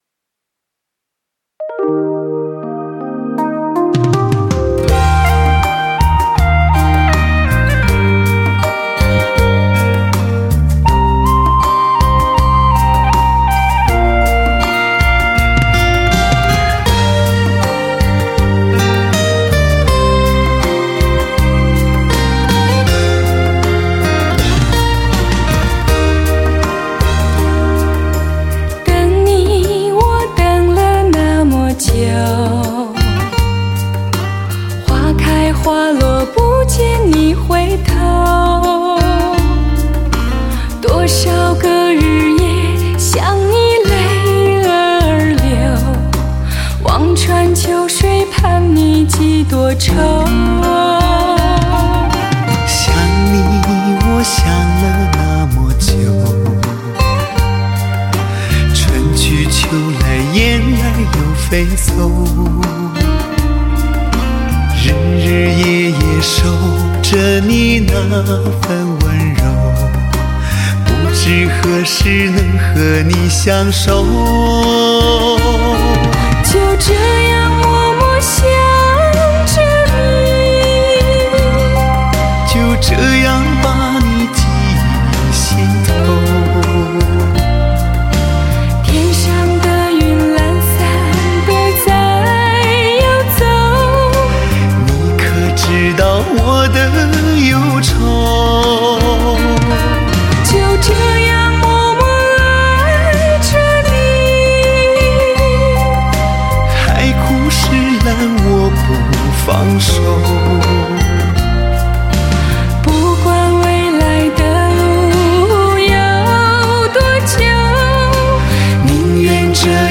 独特沧桑的声音
一部部扣人心弦的原创作品，一个独特沧桑的声音，一个男人的声音
再加上自己具有一副很有穿透力的独特磁性嗓音，更让人不得不拥有片刻的宁静，产生心灵的共鸣。